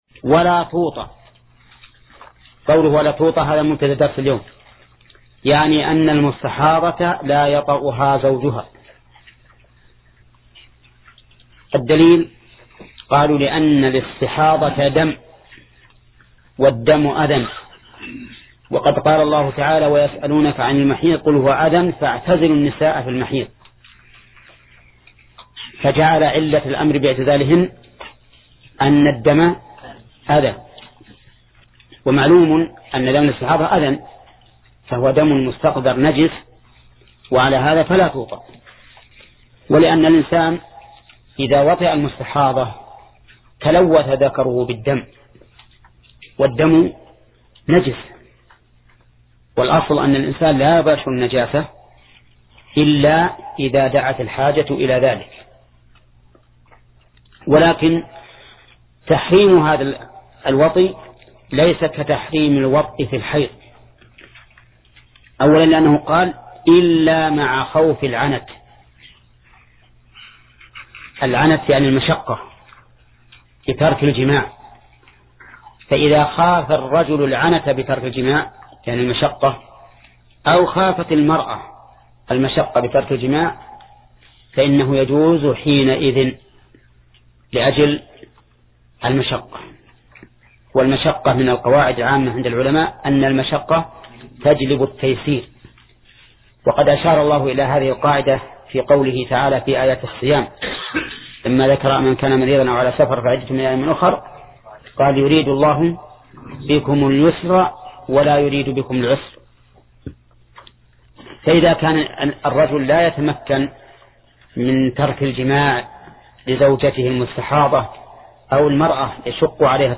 درس (26) : تتمة باب الحيض